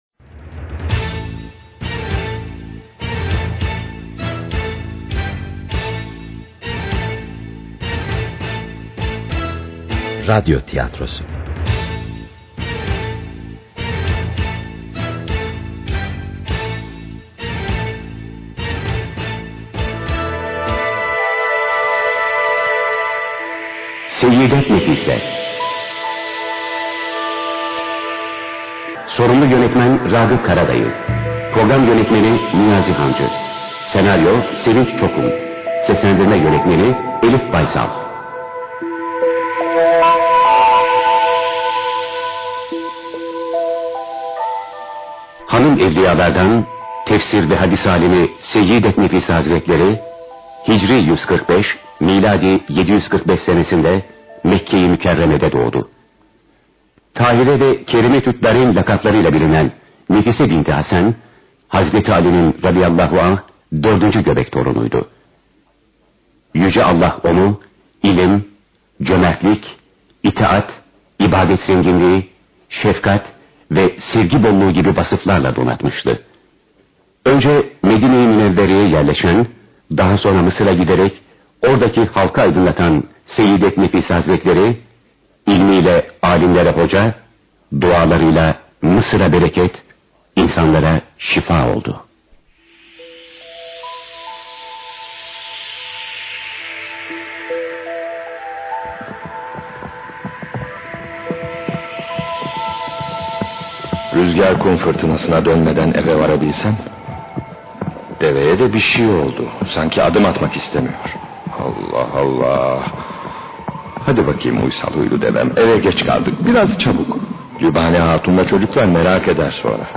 Seyyid-et-Nefise-Hazretleri-radyo-tiyatrosu-.mp3